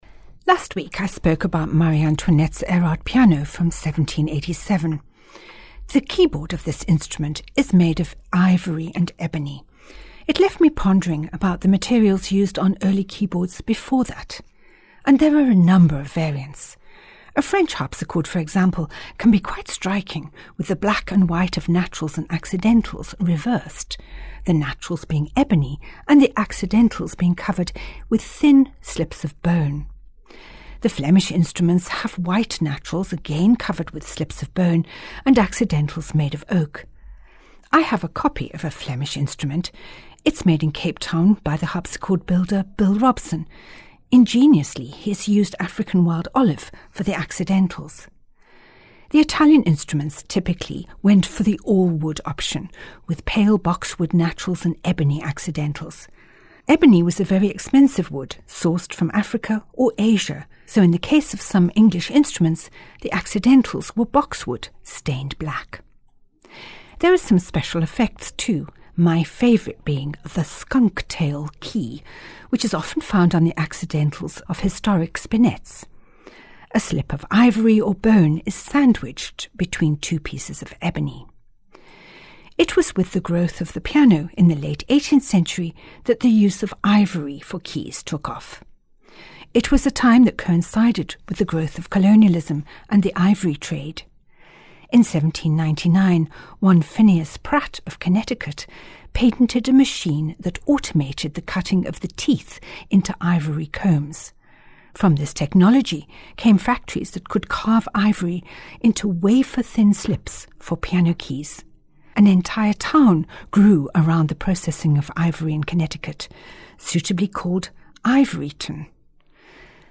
Each weekly Bon Bon is accompanied by a piece of Baroque music which ties in with the story.